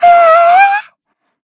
Taily-Vox-Laugh_kr.wav